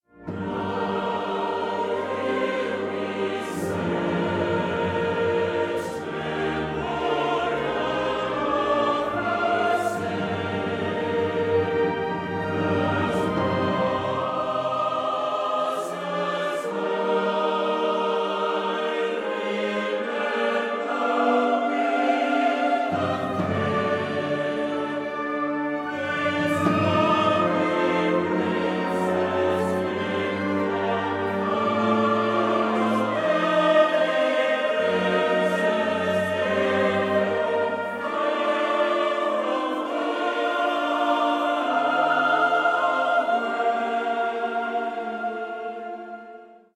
Stereo
arranged for military band